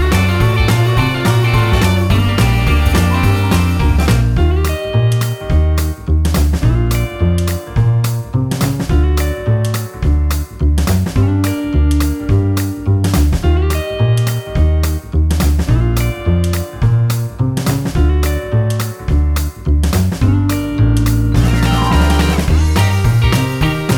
no Backing Vocals Rock 'n' Roll 3:07 Buy £1.50